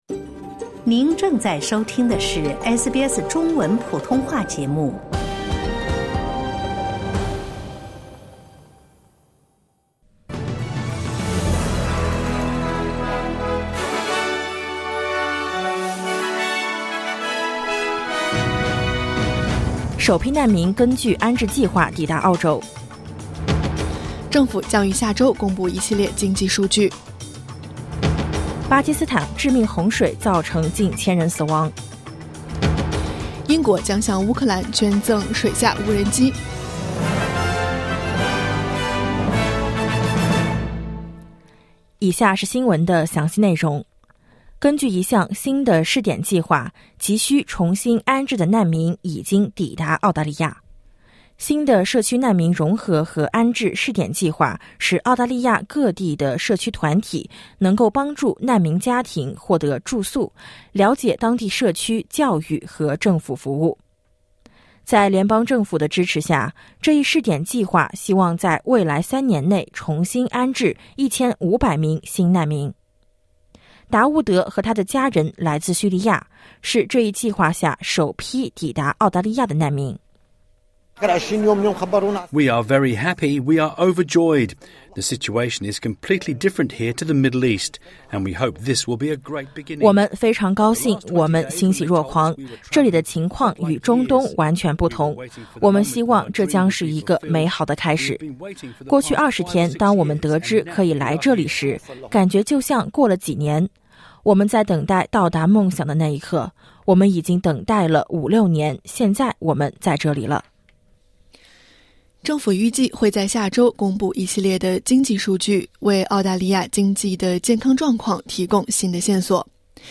SBS早新闻（2022年8月28日）